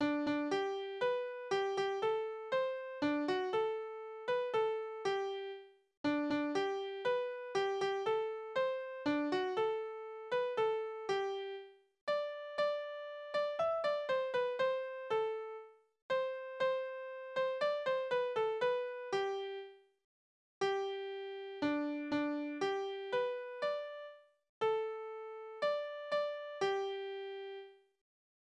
Berufslieder:
Tonart: G-Dur
Taktart: 3/4, 4/4
Tonumfang: große None
Besetzung: vokal
Anmerkung: Taktwechsel zw. Takt 4 und 5